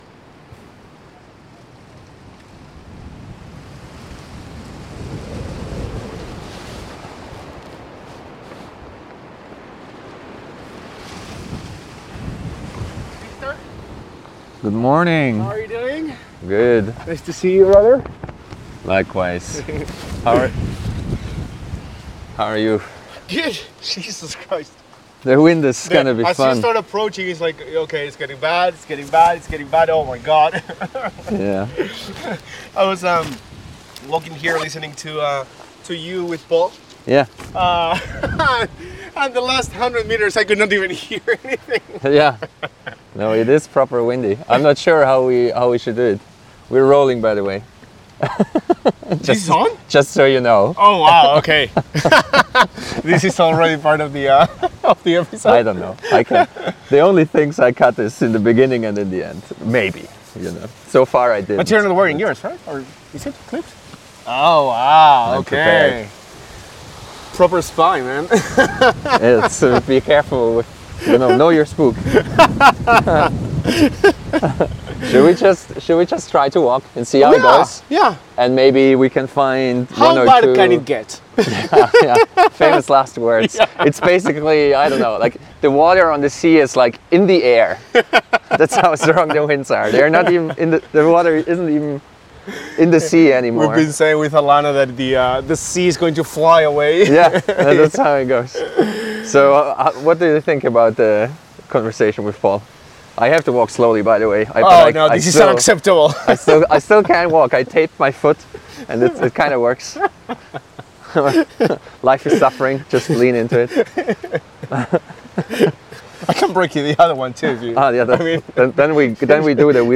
A walking dialog on what the right trade-off balance might be.